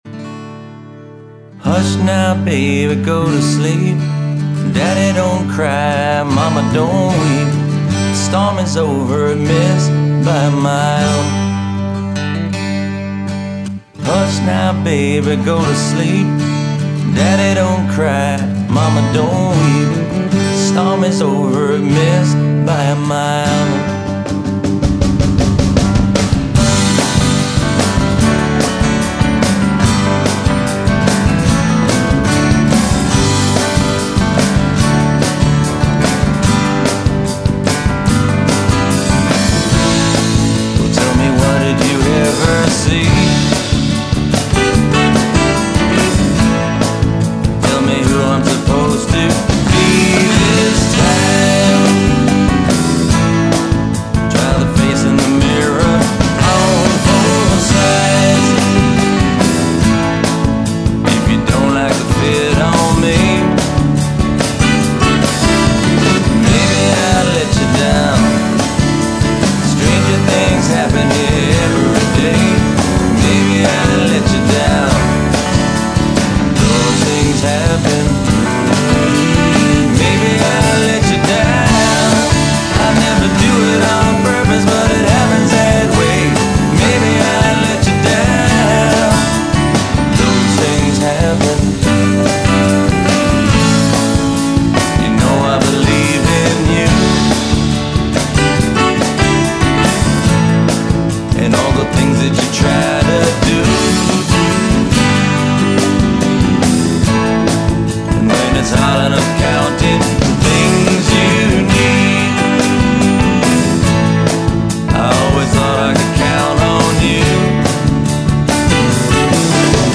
Guitars
Bass
Drums
Keys
Accordian
Horns